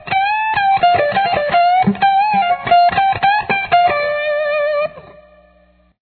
Solo Part 3